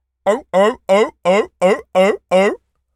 pgs/Assets/Audio/Animal_Impersonations/seal_walrus_bark_high_01.wav at master
seal_walrus_bark_high_01.wav